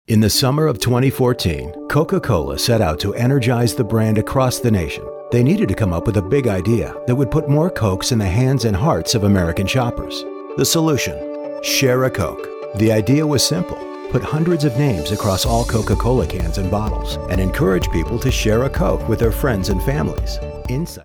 mid-Atlantic, North American English
Middle Aged
His voice is described as warm, textured, engaging, and dynamic, conveying a wide range of emotions and tones that resonate with audiences.